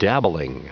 Prononciation du mot dabbling en anglais (fichier audio)
Prononciation du mot : dabbling